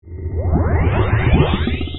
sounds / player / spell / cast 2sec v2.wav
cast 2sec v2.wav